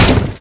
wood1.wav